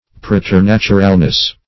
Search Result for " preternaturalness" : The Collaborative International Dictionary of English v.0.48: Preternaturalness \Pre`ter*nat"u*ral*ness\, n. The quality or state of being preternatural.